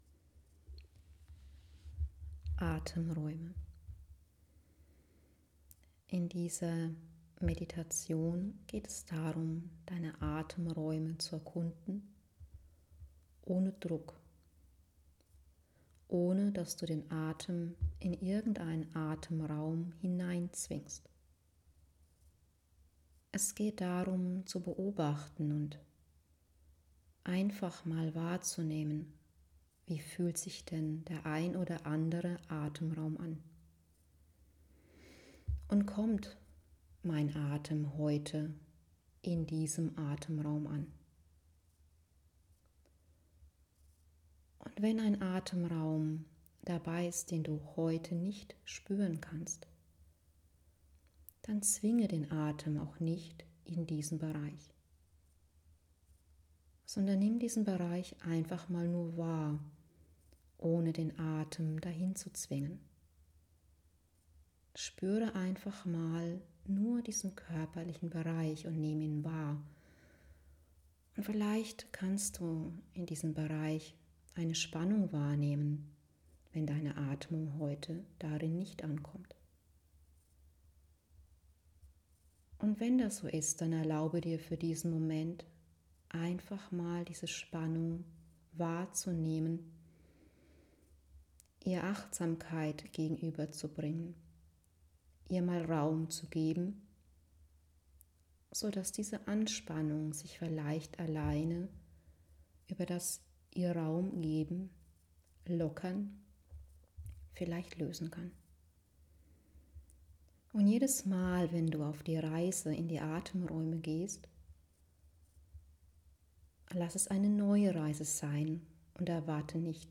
In dieser Meditation gibt es eine kleine „Basismelodie“ aus den Worten Liebe – Danke- Segnung, andere Worte kommen dazu, so dass eine kleine Sinfonie aus Frequenzen entsteht.